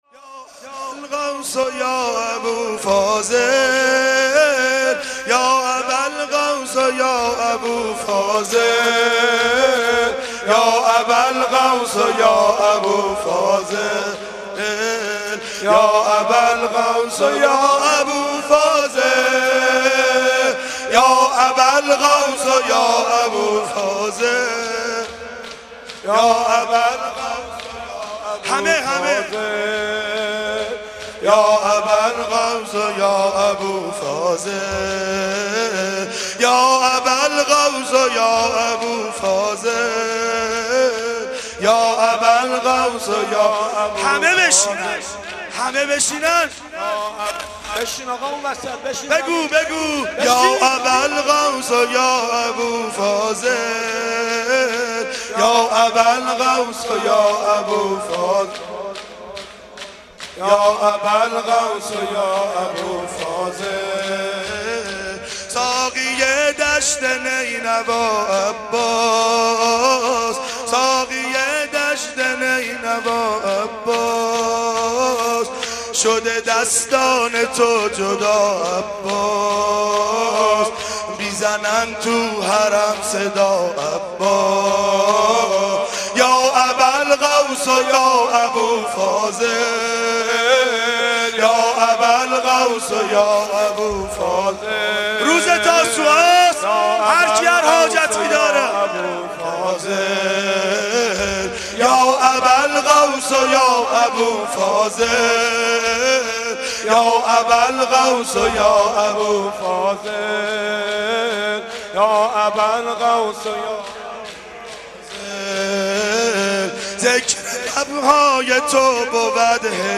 مناسبت : تاسوعای حسینی
مداح : حاج منصور ارضی قالب : شور